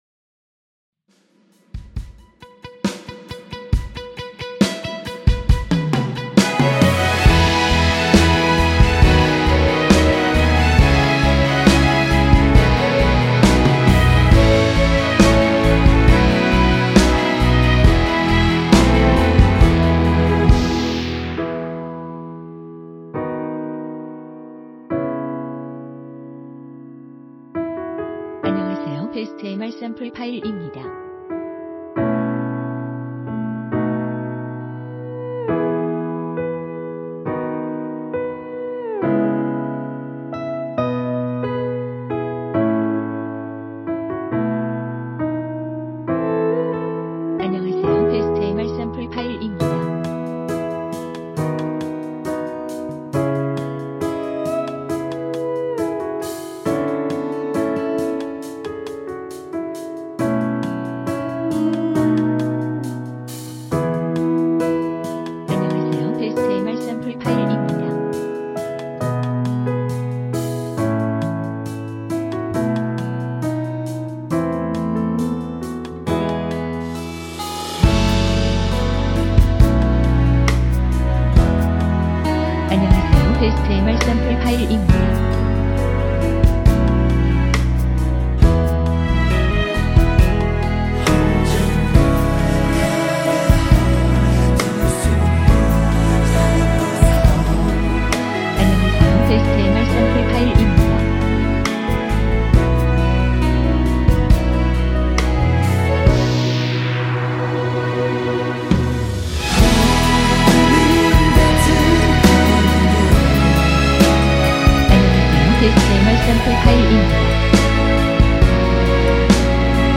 원키 코러스 포함된 MR입니다.(사운드 마커 포함된 전체 미리듣기 입니다.)